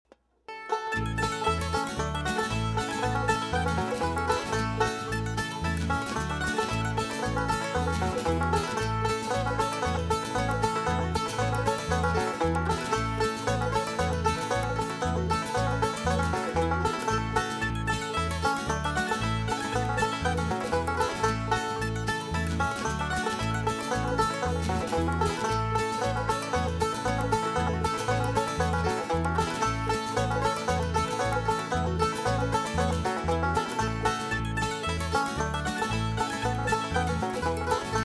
Banjo Lessons:
- "Scruggs-style" fingerpicking rolls